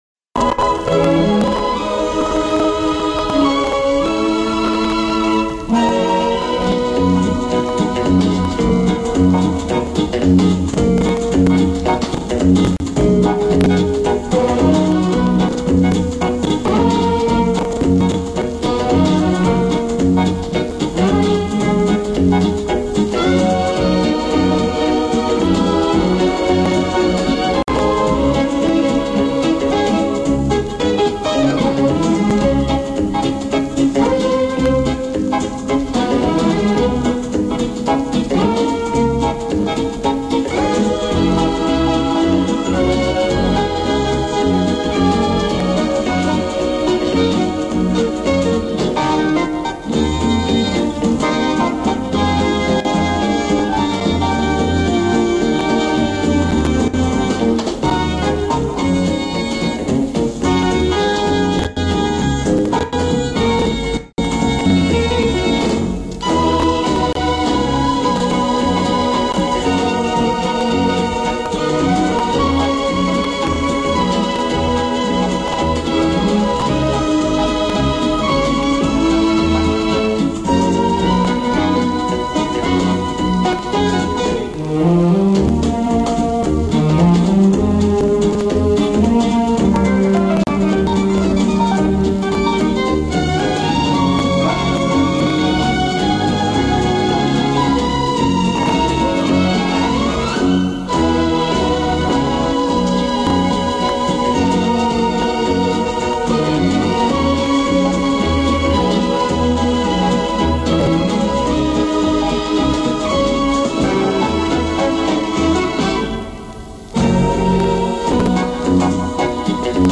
jazz
Она звучала на "Радио Петербург" 22.01.2012 г.